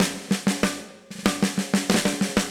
AM_MiliSnareB_95-01.wav